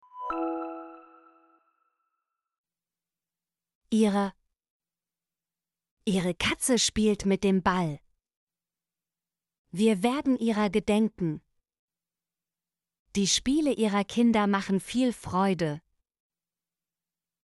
ihrer - Example Sentences & Pronunciation, German Frequency List